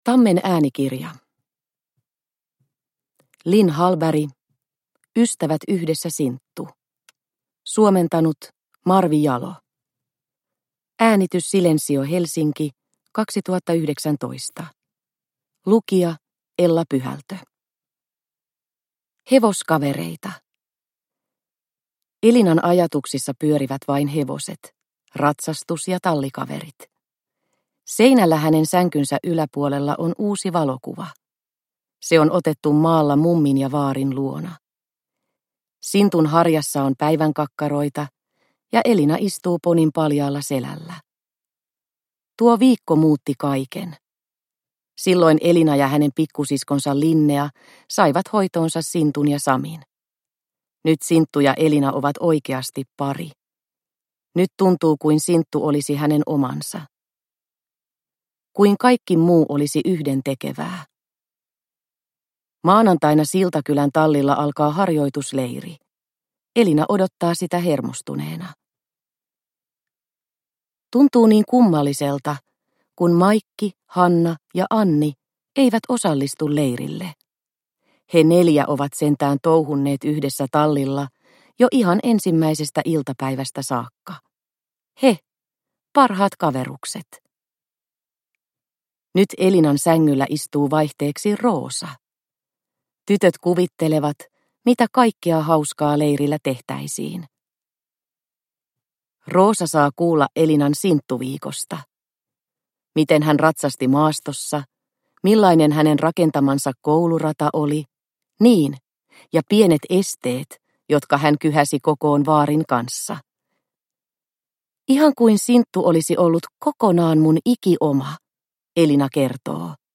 Ystävät yhdessä, Sinttu – Ljudbok – Laddas ner